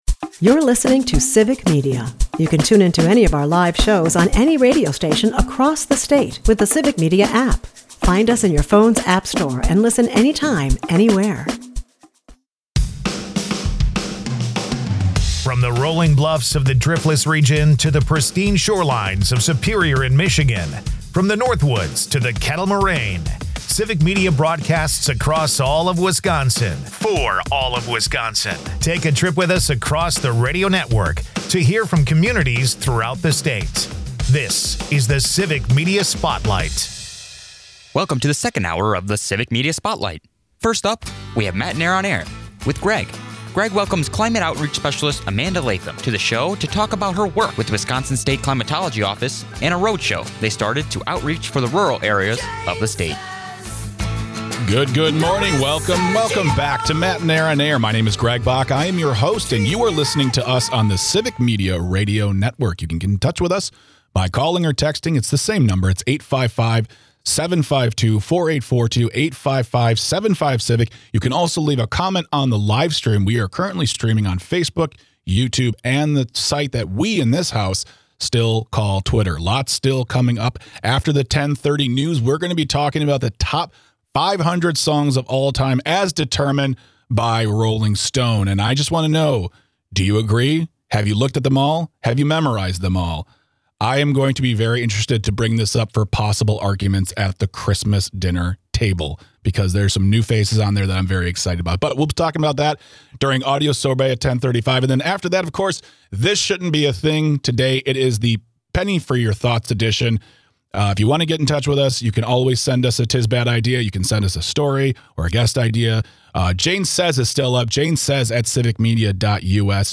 Political commentary rounds out the hour
featuring an interview